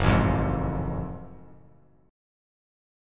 Listen to F#mM9 strummed